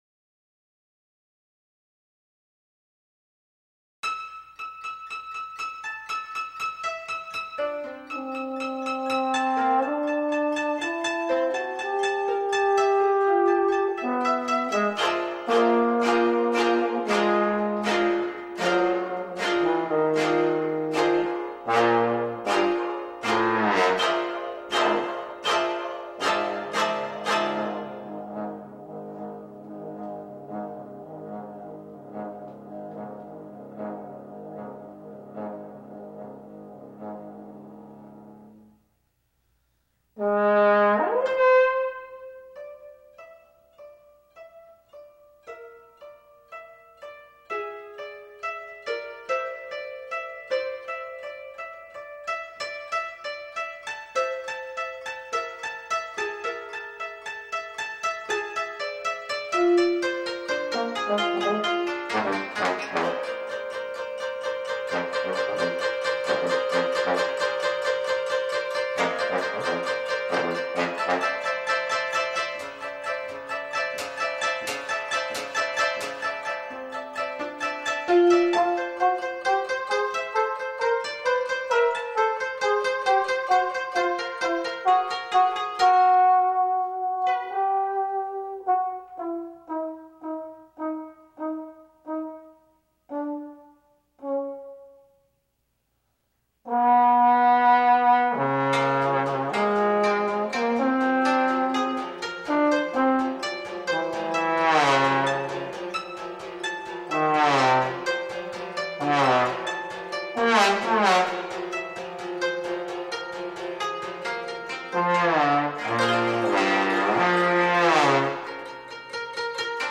two movements for trombone and koto
recorded in Boston with: